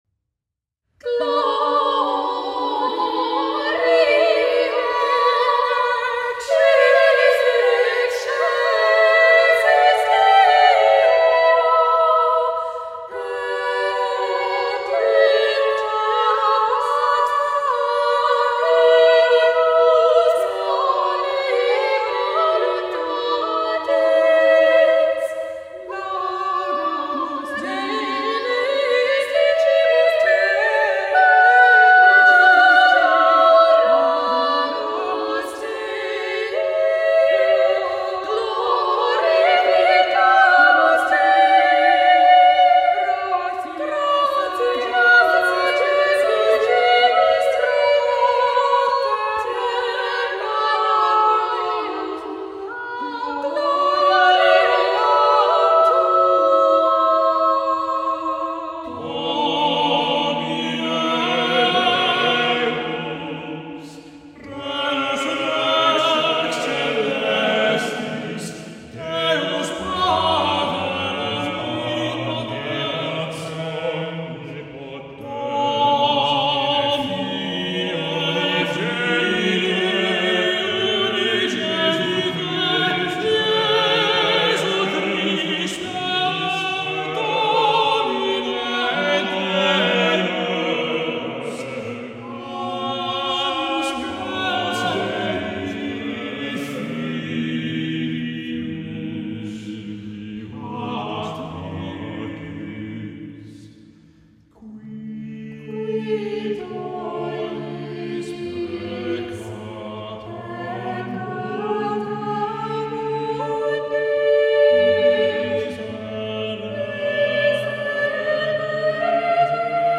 for two sopranos, alto, tenor, baritone and bass